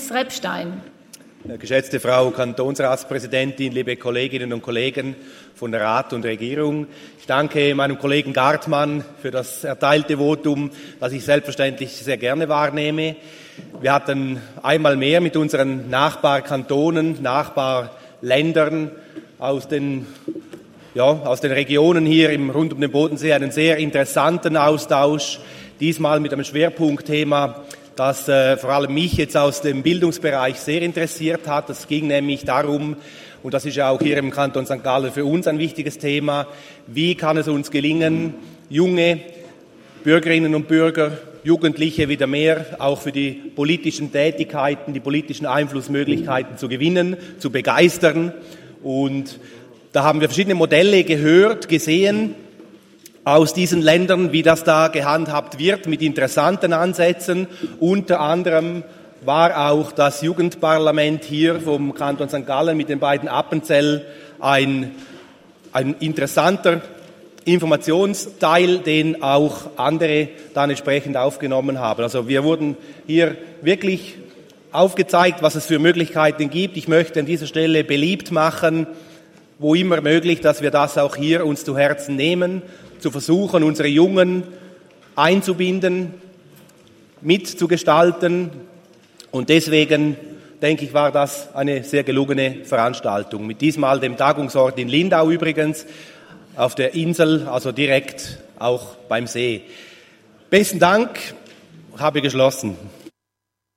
14.6.2023Wortmeldung
Session des Kantonsrates vom 12. bis 14. Juni 2023, Sommersession